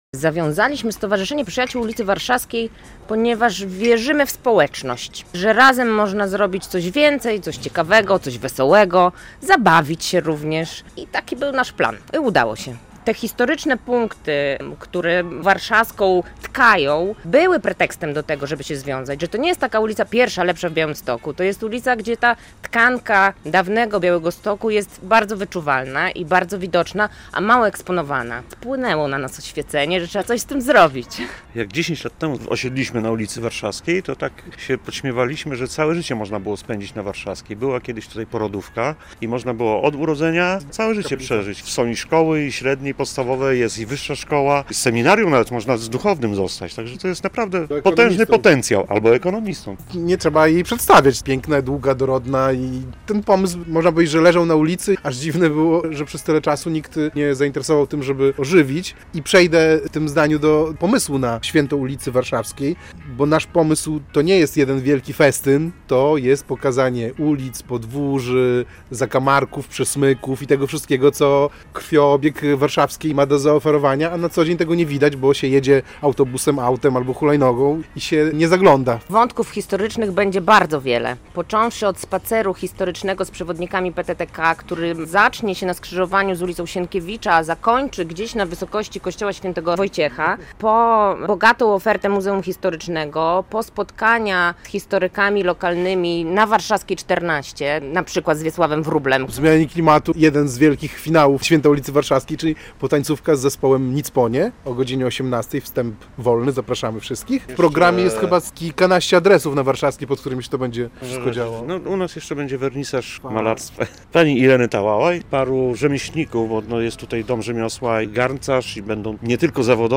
Święto ulicy Warszawskiej - relacja